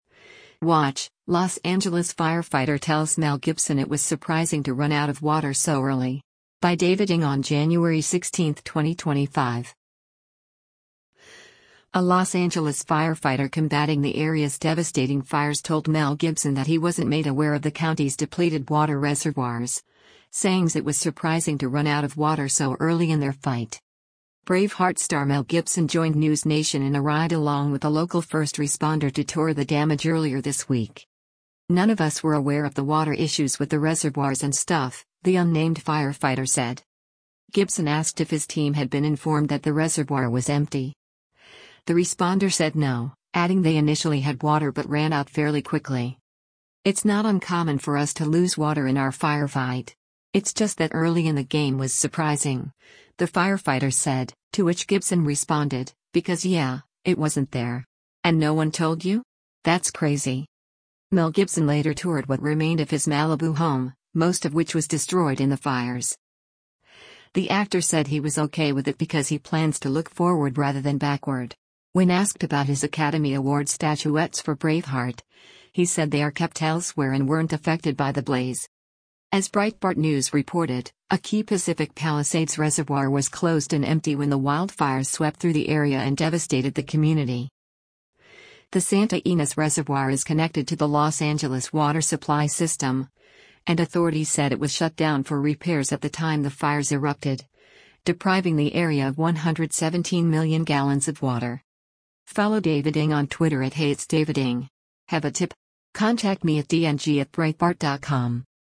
Braveheart star Mel Gibson joined NewsNation in a ride-along with a local first responder to tour the damage earlier this week.